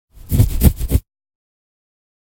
scratch.ogg.mp3